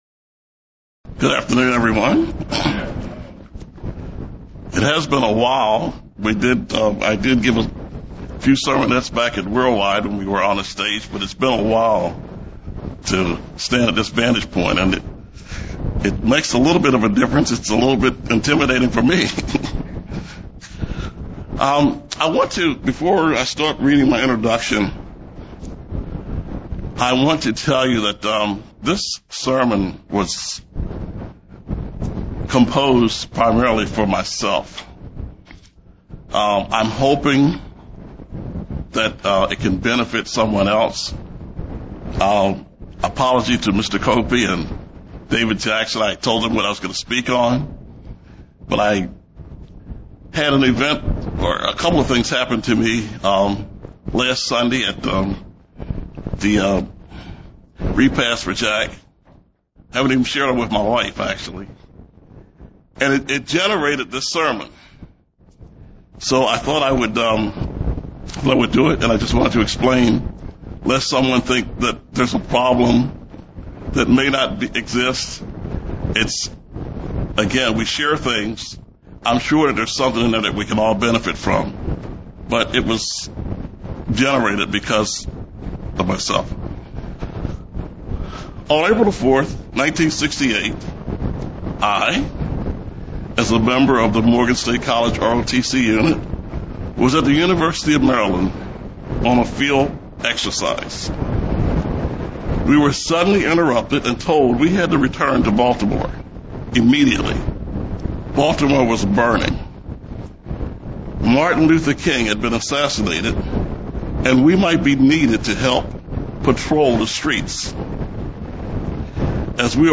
Given in Columbia, MD
UCG Sermon Studying the bible?